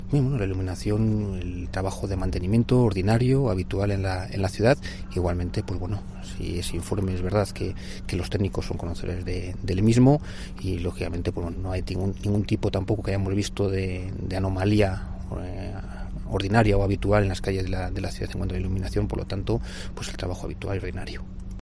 José Ramón Budiño, portavoz Equipo de Gobierno. Iluminación